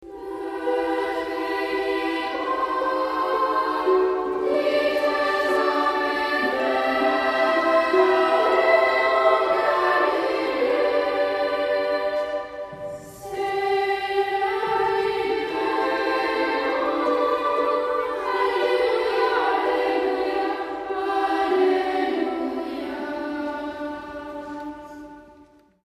Tonart(en): D (tonales Zentrum um)